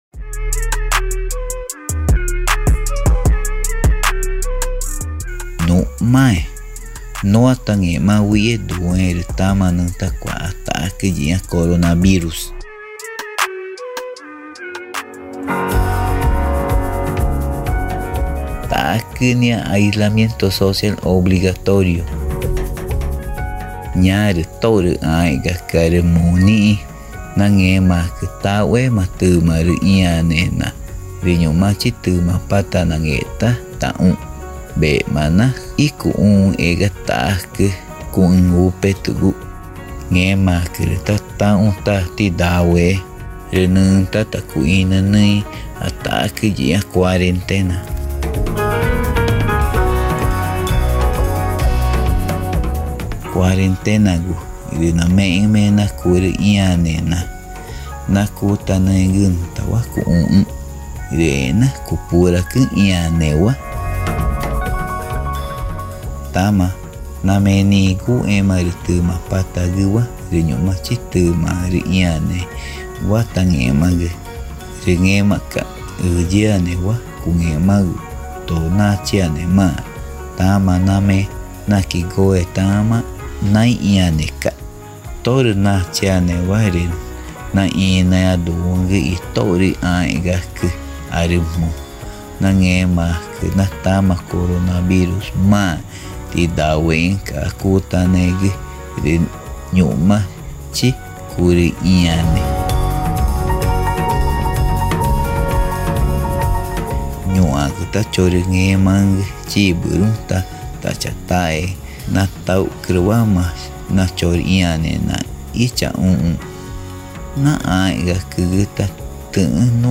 Radio Spots in Tikuna
Microprograma-2-Coronavirus-TICUNA-GRL.mp3